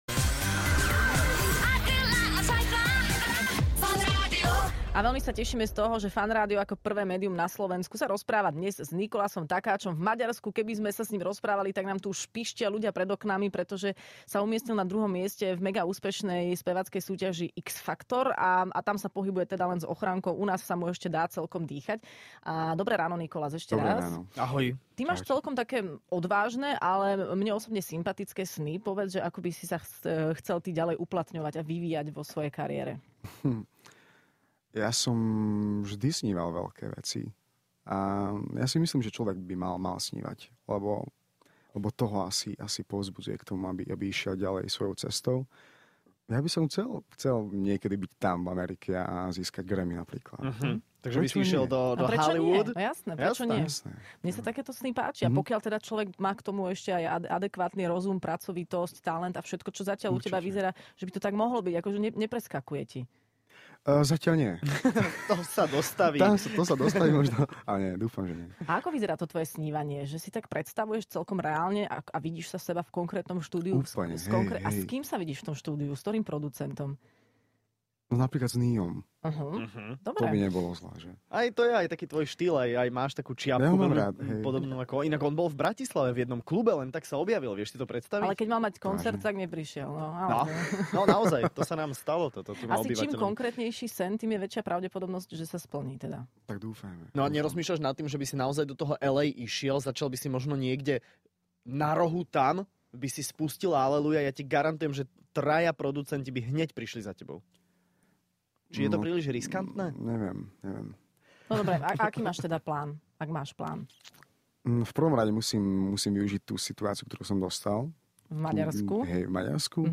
Hosť